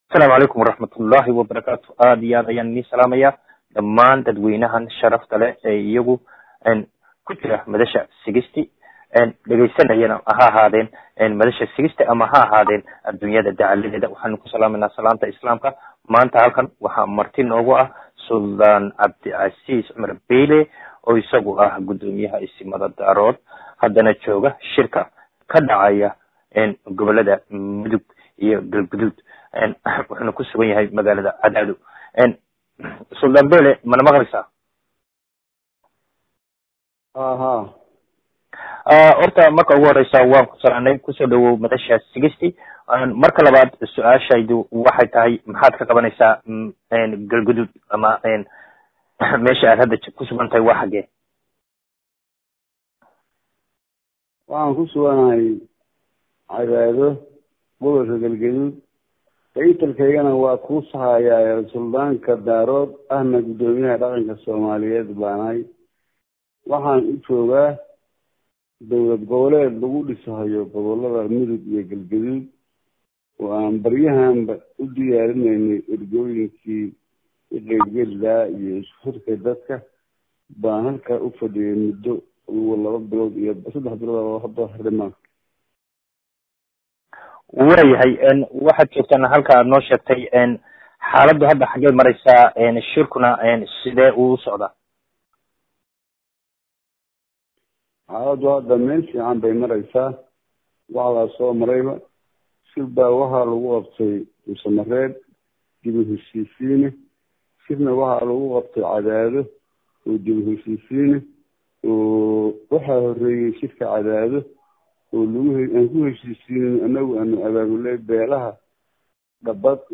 Hadaba wareysiga iyo jawaabta oo dhan halkaan kadhageyso.